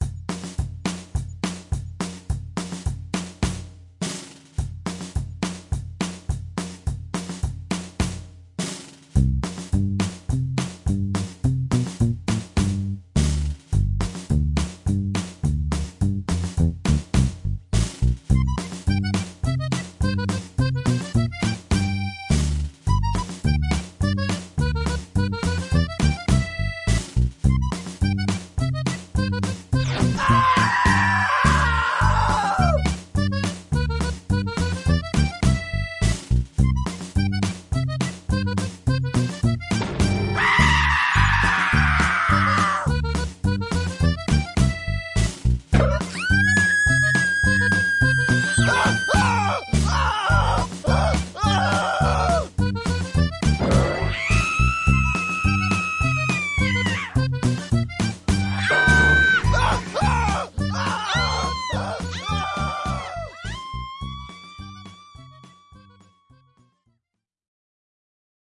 管子的声音 " 管子11
描述：PVC管的声音来自于一个厕纸卷筒的东西。在末端有一个旋钮，你可以拉开，产生这种声音。 录音时使用的是akg c3000.Mackie XDR pre;(
标签： 卡通 卡通声音 漫画 FX 管材 声音 管道 SFX 声音效果 声音效果
声道立体声